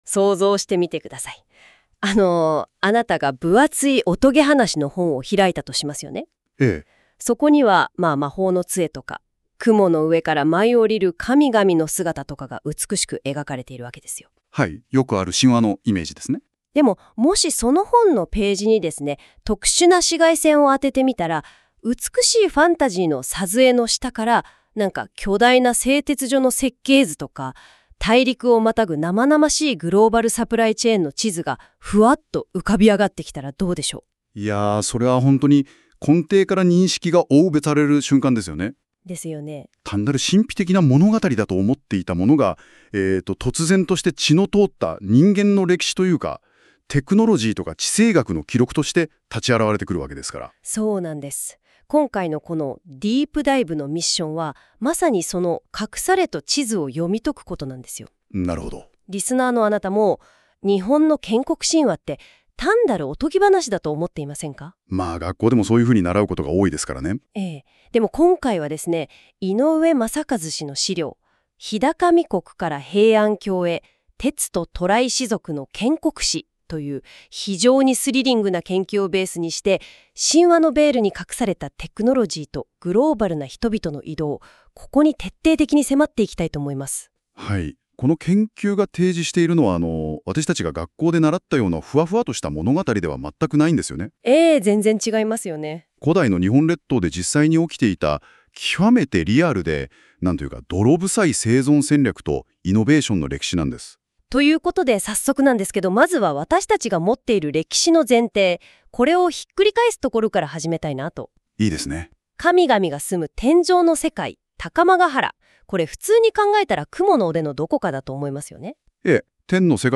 (注）出版本の音声による概要解説です。